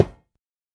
1.21.5 / assets / minecraft / sounds / block / iron / break2.ogg
break2.ogg